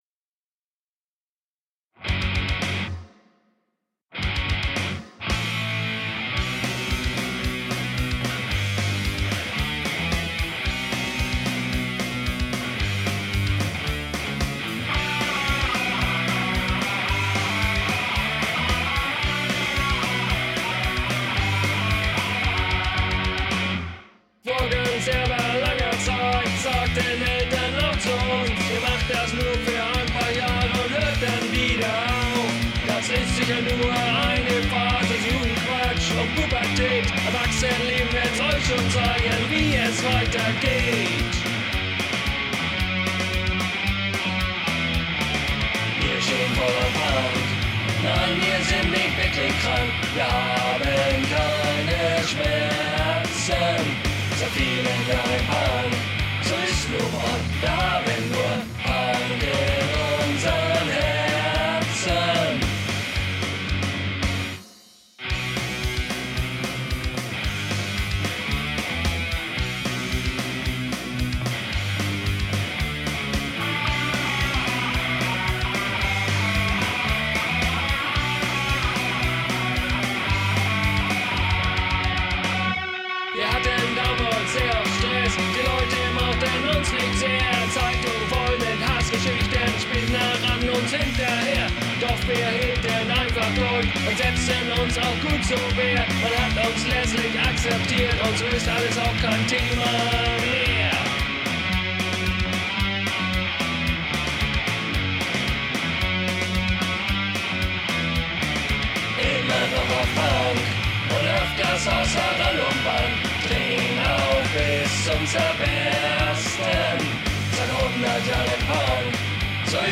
low quality web version
Lead Vocals
Doom Bass
Death Drums